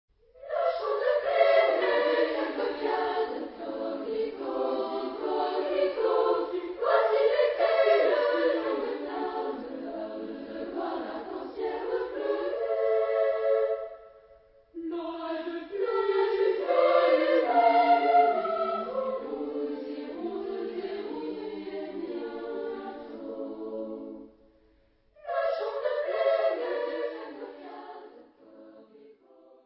Genre-Style-Forme : Moderne ; Enfants ; Profane ; Suite
Tonalité : la majeur